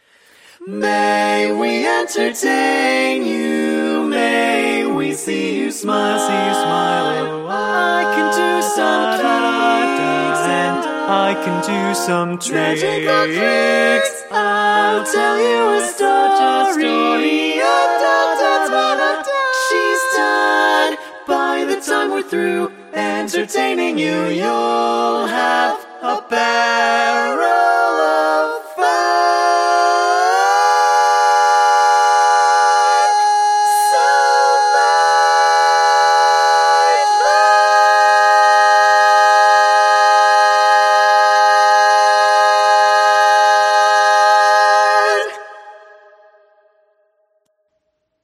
Key written in: A♭ Major
How many parts: 4
Type: Female Barbershop (incl. SAI, HI, etc)
All Parts mix: